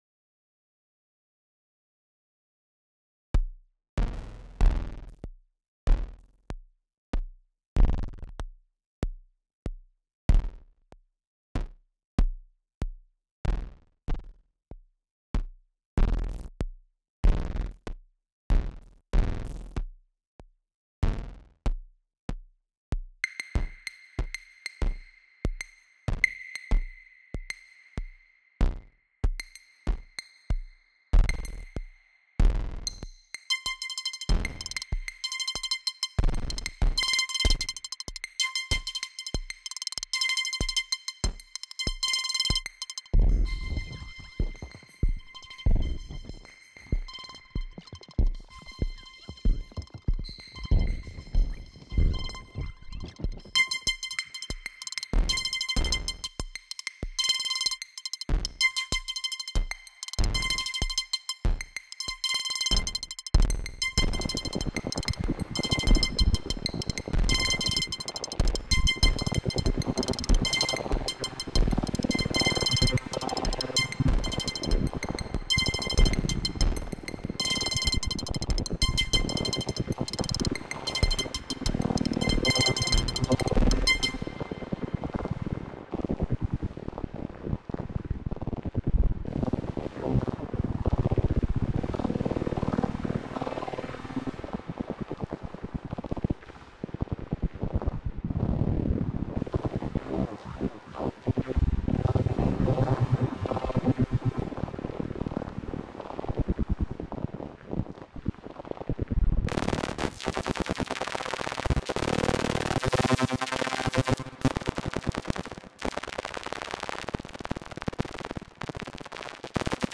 Quick percussive SY SWARM attempt.